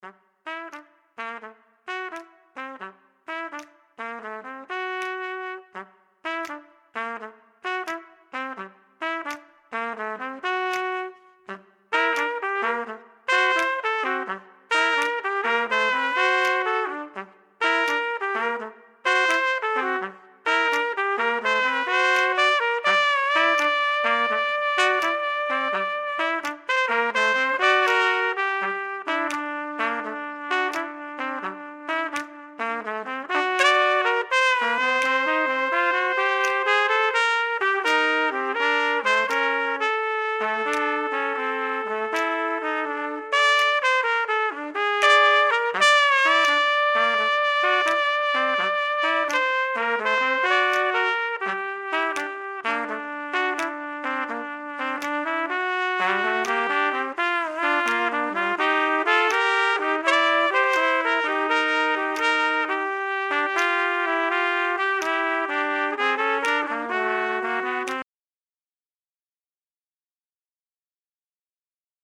Trompet
Samen met mijn neven de cornet en de bugel vormen wij het licht koper. De heldere klanken van ons trompetgeschal zijn...